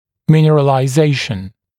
[ˌmɪnərəlaɪ’zeɪʃn][ˌминэрэлай’зэйшн]минерализация